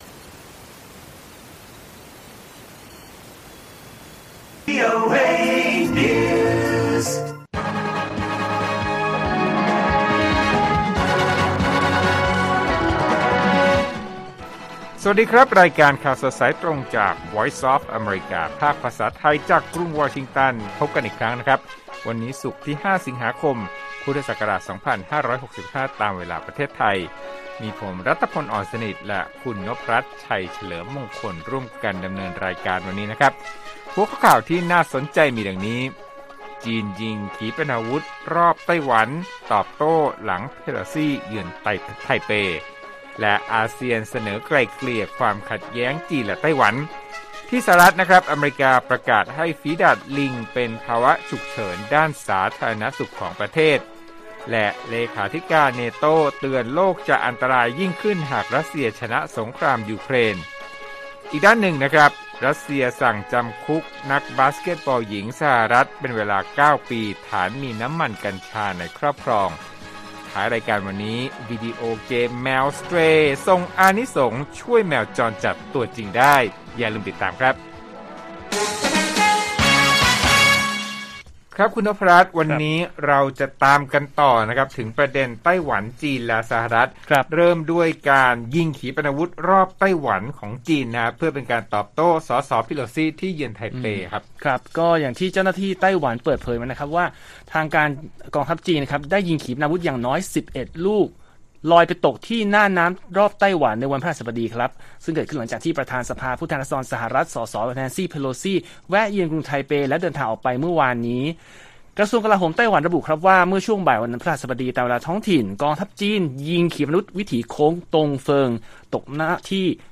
ข่าวสดสายตรงจากวีโอเอไทย 6:30 – 7:00 น. วันที่ 5 ส.ค. 65